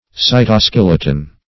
cytoskeleton \cy`to*skel"e*ton\
(s[imac]`t[-o]*sk[e^]l"[i^]*t'n), n. (Cell Biology)